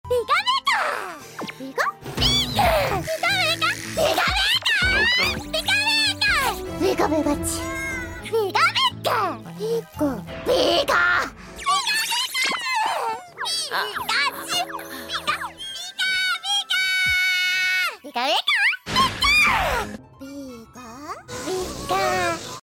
the voice of Mp3 Sound Effect Ikue Ōtani, the voice of Pikachu since 1997, is back in Pokémon Ultimate Journeys: Part 1, now streaming on Netflix!